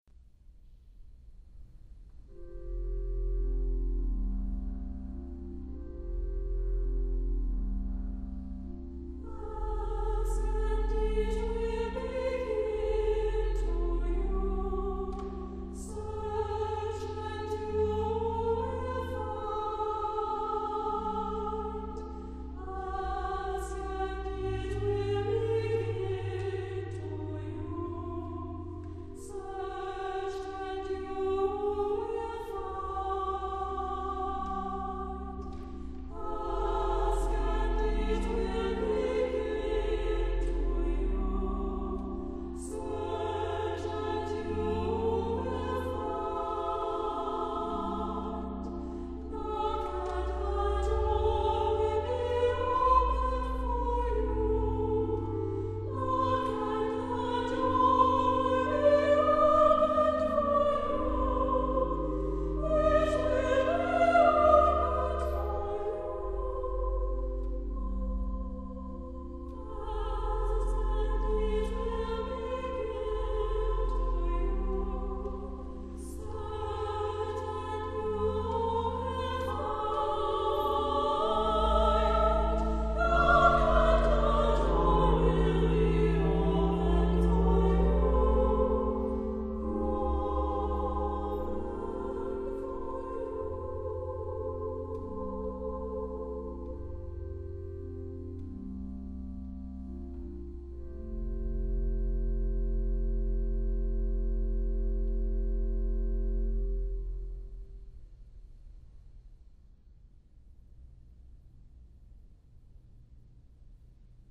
• Music Type: Choral
• Voicing: Treble Voices
• Accompaniment: Organ
creates an ostinato effect, framing this anthem in ABA form